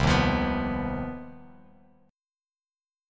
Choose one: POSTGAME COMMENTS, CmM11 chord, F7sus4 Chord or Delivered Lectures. CmM11 chord